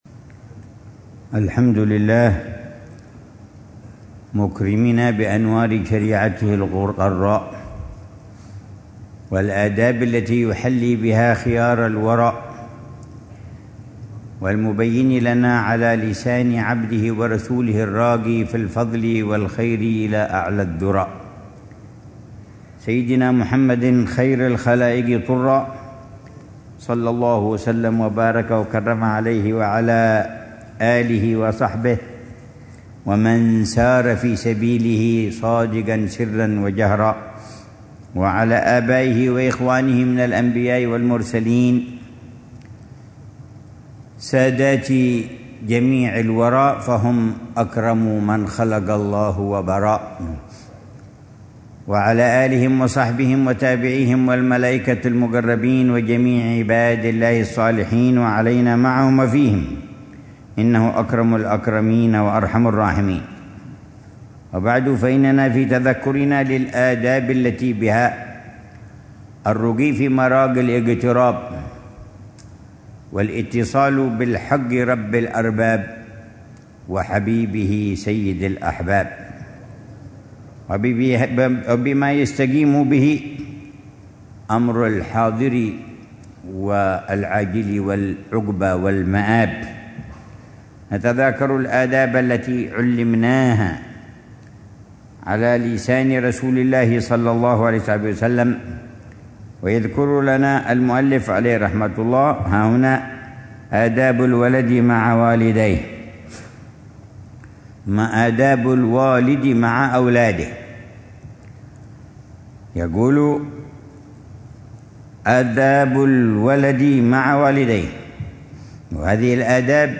الدرس الخامس والأربعون من شرح العلامة الحبيب عمر بن حفيظ لكتاب الأدب في الدين لحجة الإسلام الإمام محمد بن محمد الغزالي، ضمن الدروس الصباحية لأ